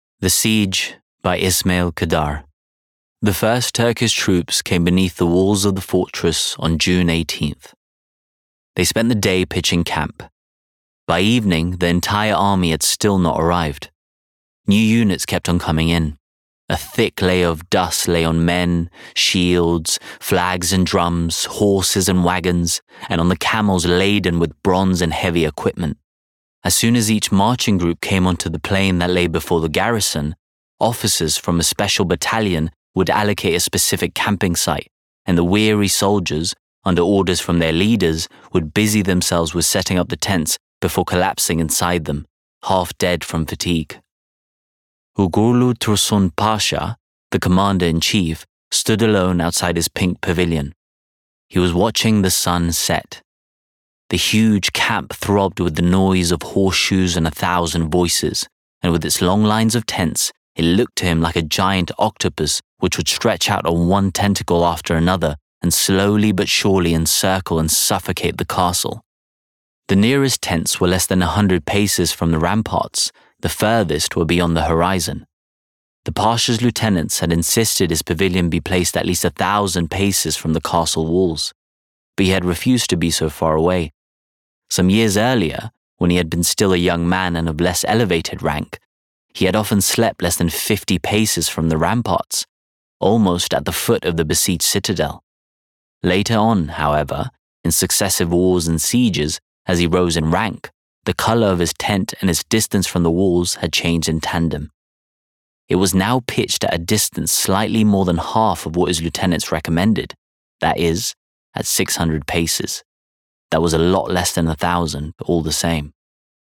English neutral, Albanian, Rich, Warm, Textured, Expressive, Versatile, Global, Adaptable